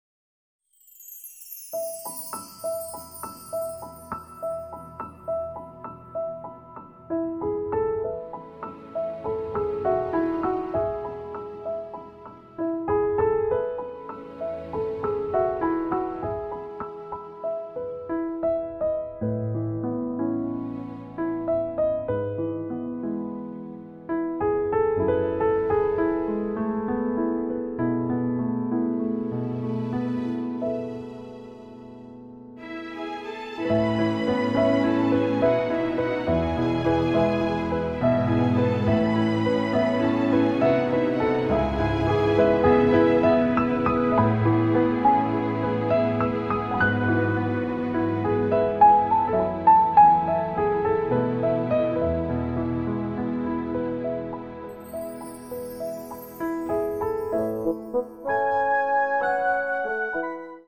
最初にピアノのみのデモ音源を聴いていただき、アレンジを加えて最終的に完成したのがこちらです♪